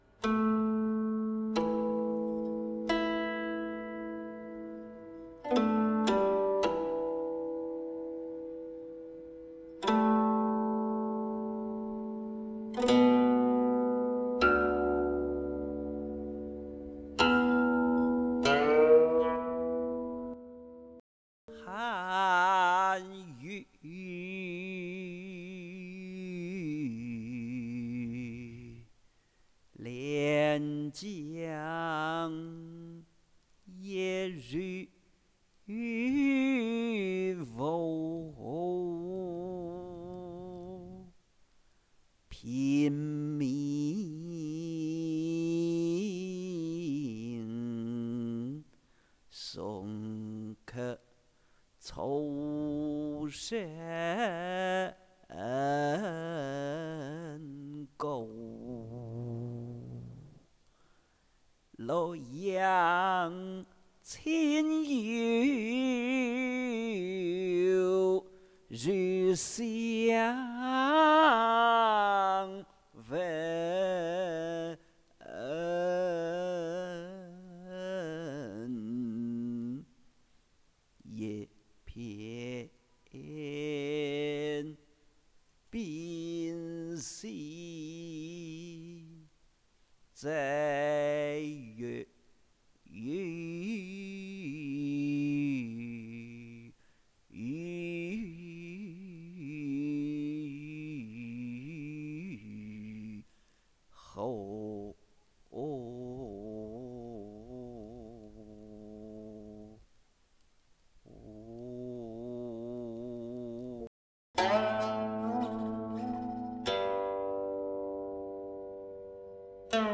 吟哦